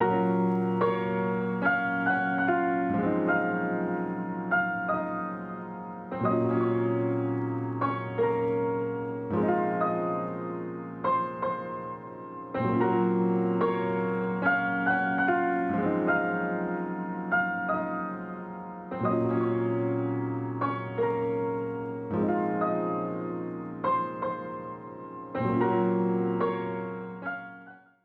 jazz keys 2.wav